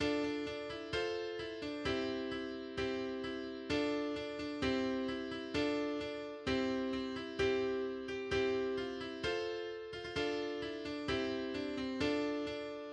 Spottlied über die Heimat Garetien